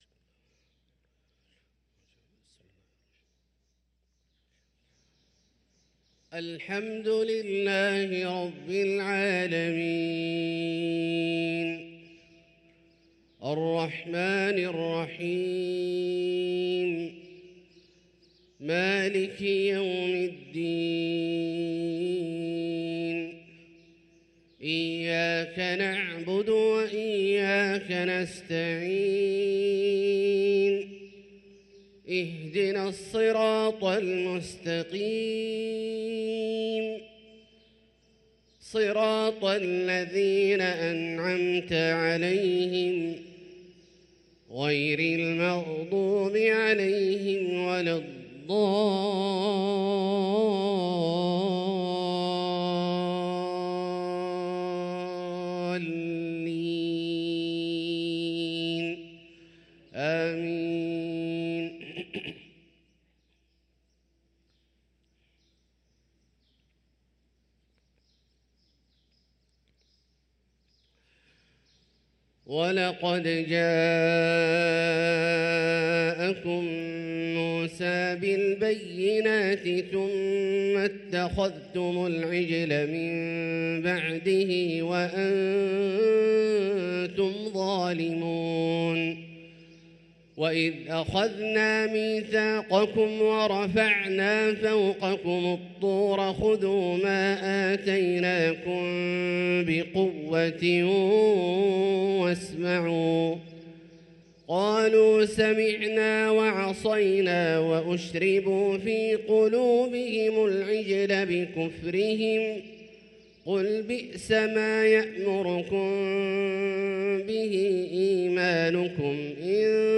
صلاة الفجر للقارئ عبدالله الجهني 24 جمادي الآخر 1445 هـ
تِلَاوَات الْحَرَمَيْن .